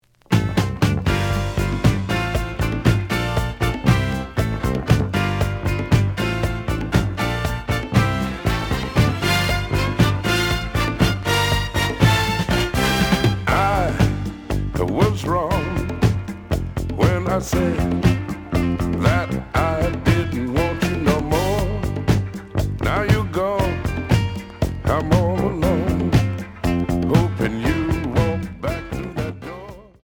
The audio sample is recorded from the actual item.
●Genre: Disco
B side plays good.)